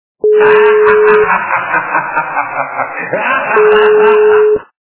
» Звуки » Люди фразы » Зловещий - смех
При прослушивании Зловещий - смех качество понижено и присутствуют гудки.
Звук Зловещий - смех